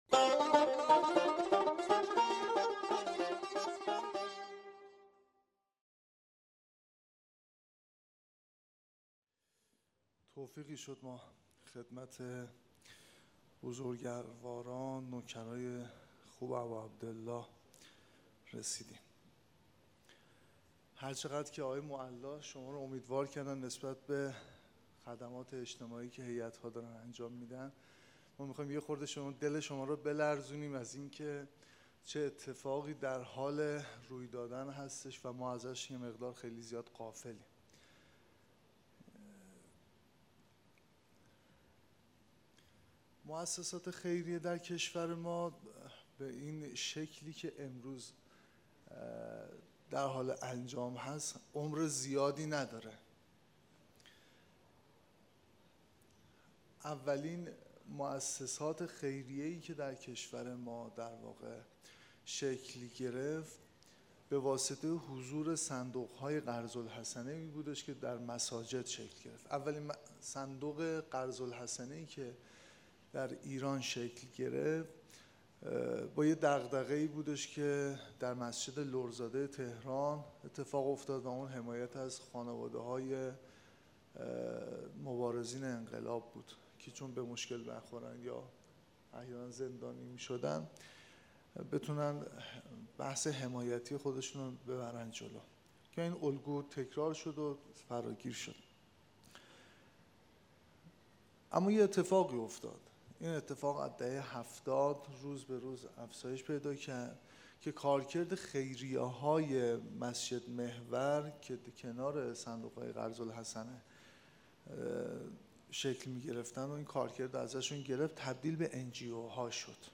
سخنرانی | پشت نقاب خیریه‌ها
دومین اجلاس رابطان جامعه ایمانی مشعر